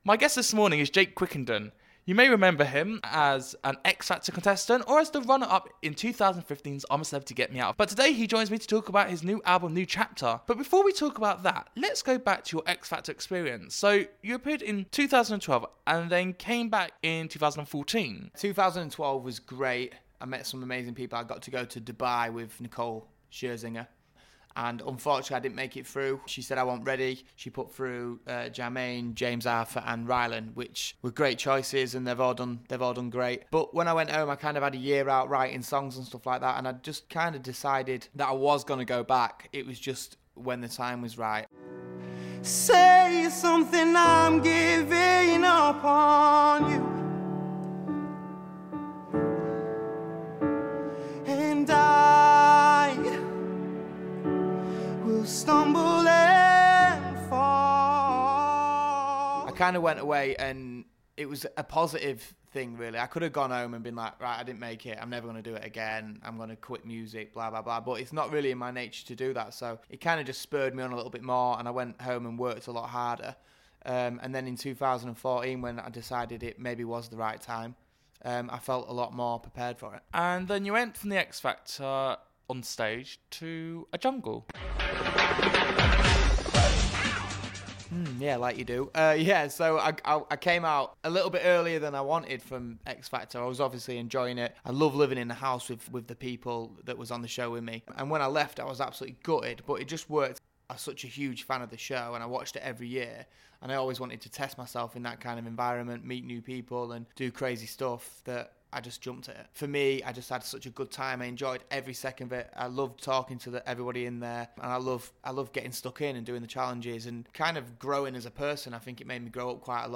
Interview with Jake Quickenden